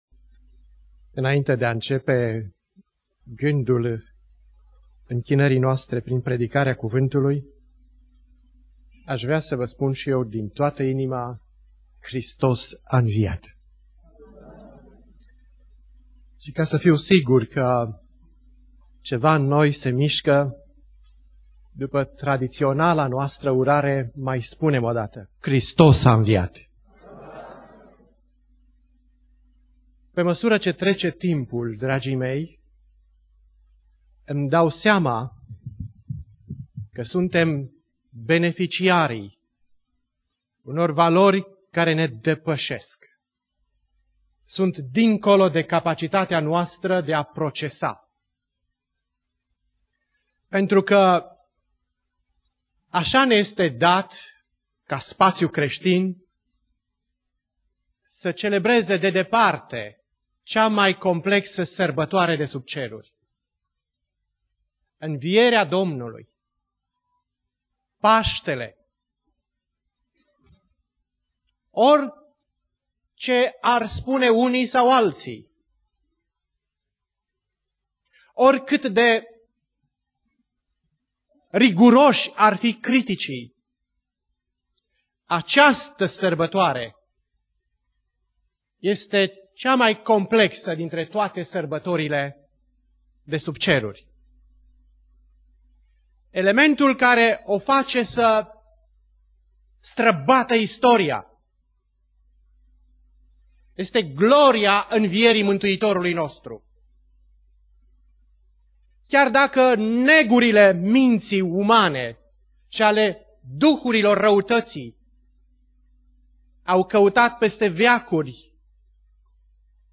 Predica Exegeza/Aplicatie - Isaia 53